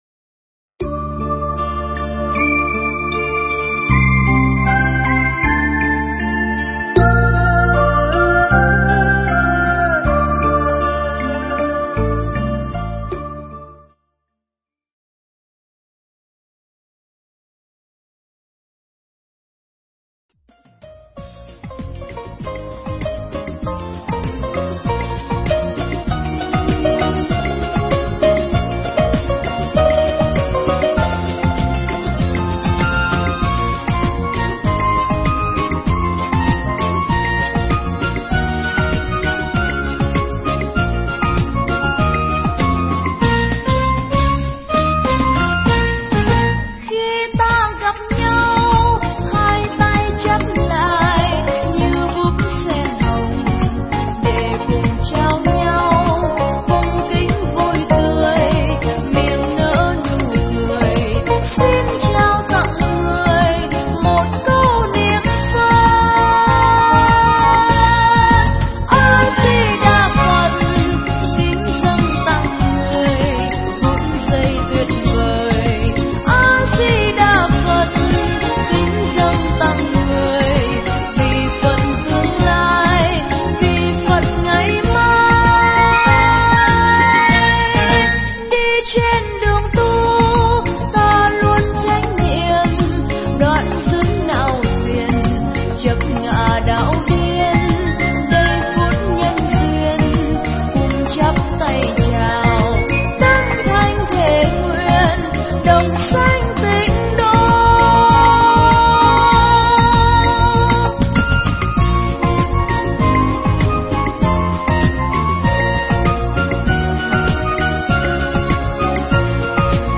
Nghe Mp3 thuyết pháp Chuyển Hóa Khổ Đau
Mp3 pháp thoại Chuyển Hóa Khổ Đau